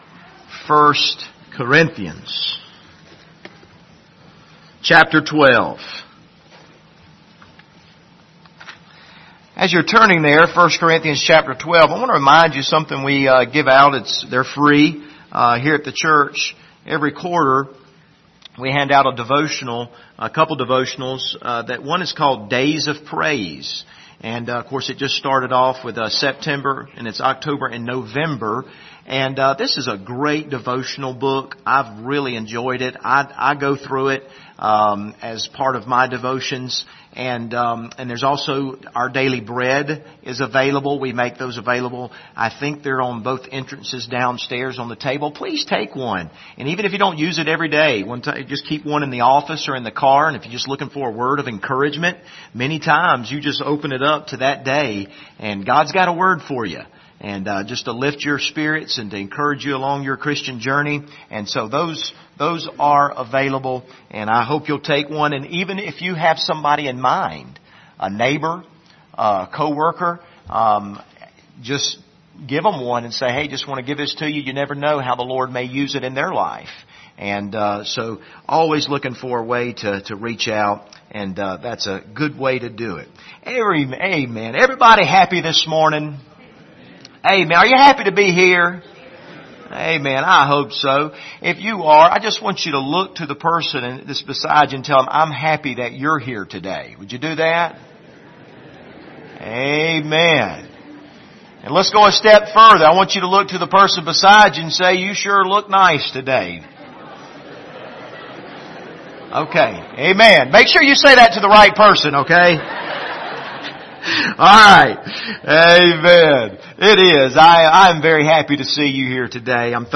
Passage: I Corinthians 12:7-11 Service Type: Sunday Morning